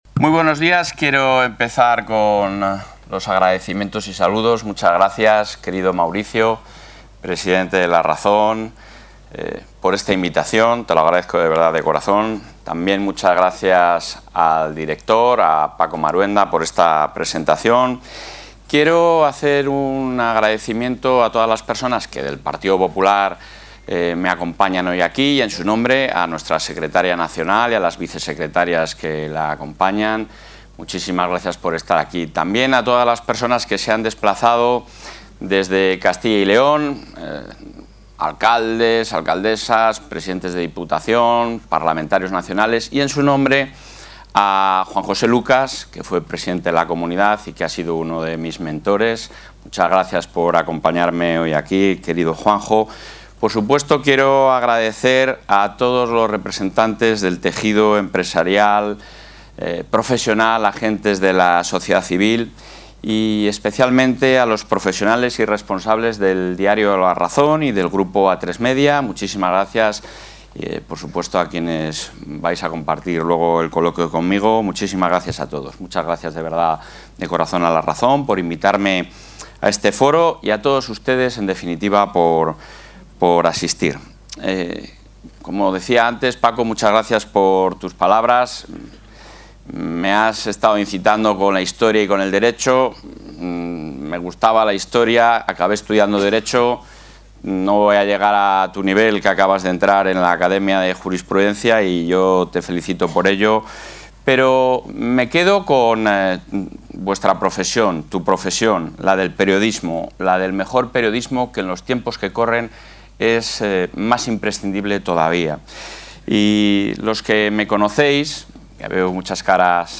El presidente de la Junta de Castilla y León, Alfonso Fernández Mañueco, ha protagonizado hoy en Madrid un acto informativo...
Intervención del presidente de la Junta.